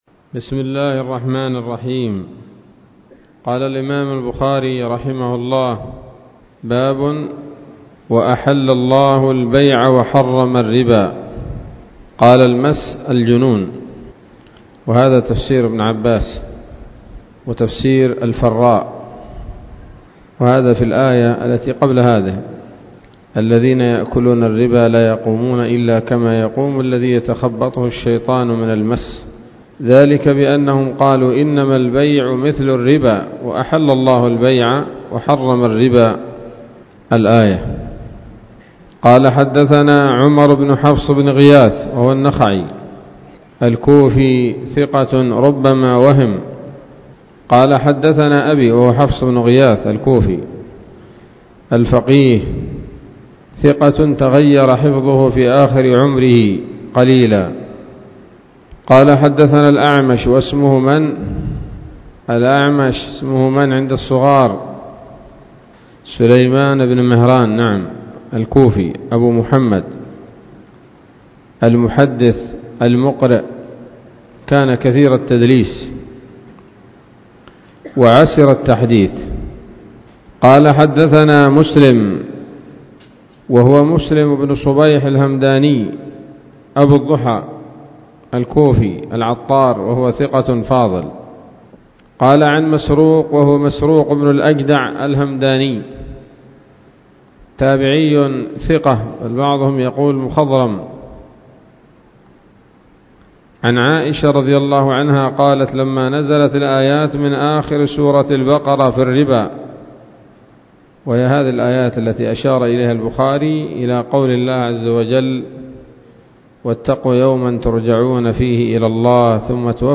الدرس الثاني والأربعون من كتاب التفسير من صحيح الإمام البخاري